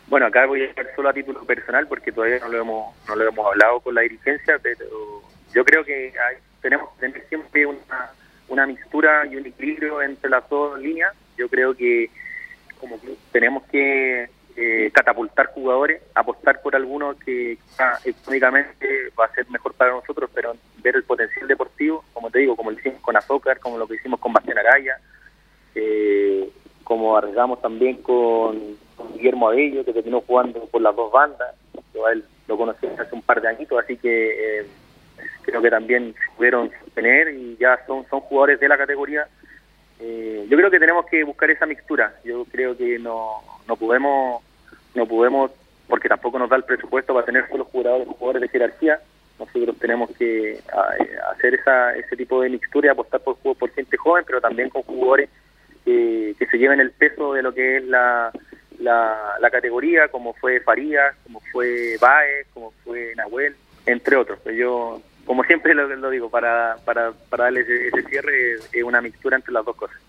en diálogo con Radio Cappissima, explicó que esperan moverse con anticipación en materia de fichajes, para así, evitar sobreprecios.